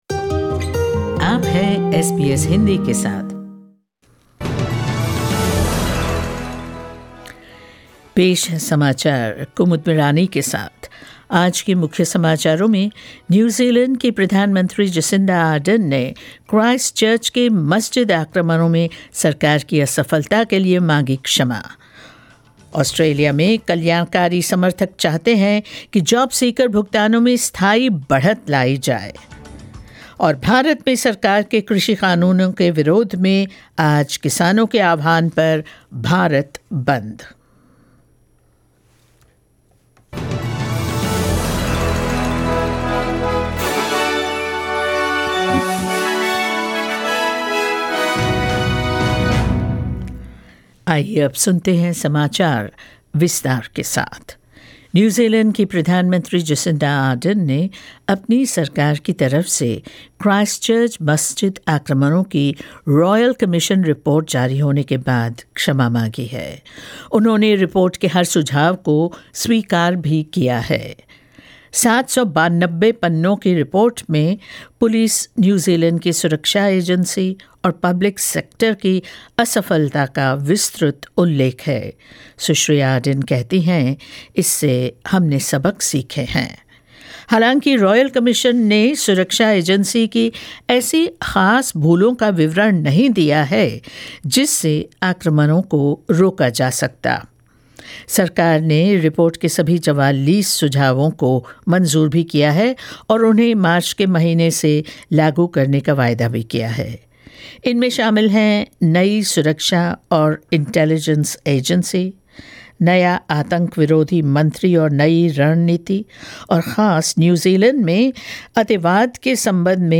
News in Hindi 8 December 2020